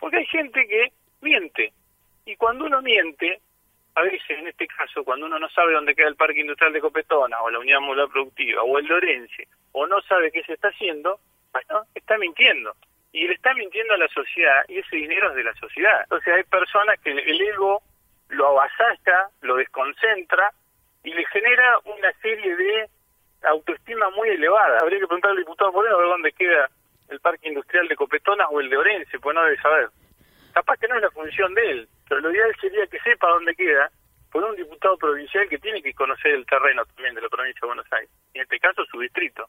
En una entrevista por Radio 3 y tras apuntalar su posible postulación como intendente en 2023, el funcionario explicó los avances registrados en los «parquecitos industriales» y aclaró que no mantiene relación con el legislador desde hace un año y medio por ese antecedente.